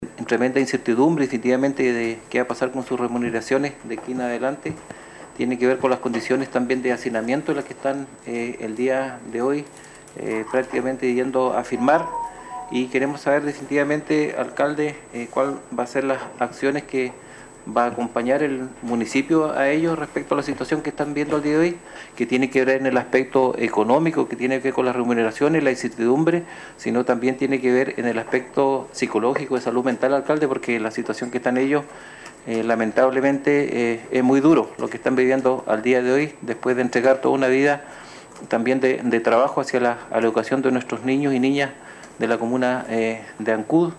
A propósito de este drama que están viviendo estos funcionarios el concejal Alex Muñoz manifestó que se hace necesario apurar las soluciones porque la situación se torna cada día, más dramática.
Todos estos alcances sobre este problema por el que atraviesan los 29 funcionarios no reconocidos por la corporación municipal fueron tratados en la sesión de concejo realizada este lunes en Ancud y que correspondió al tercer llamado para constituir la reunión, luego que en las dos anteriores, no hubo Quorum para iniciarla.